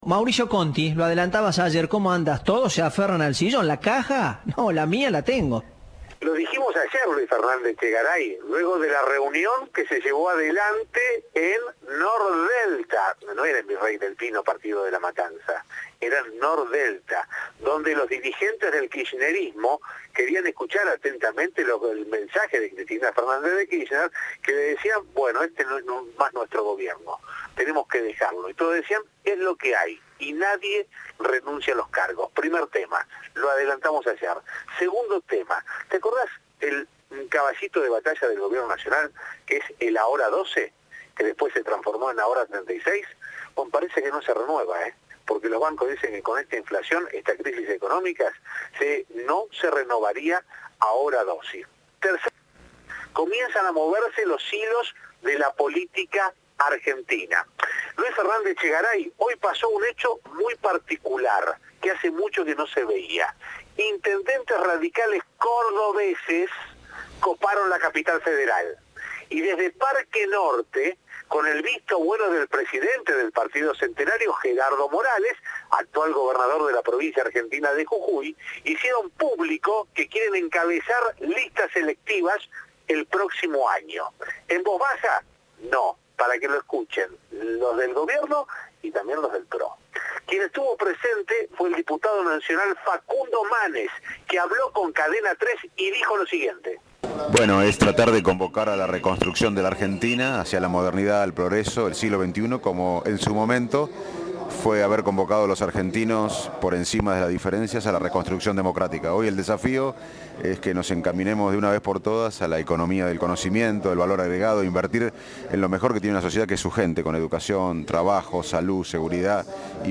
Audio. Facundo Manes y Ramón Mestre hablaron de lo que se propone el radicalismo a futuro
Informe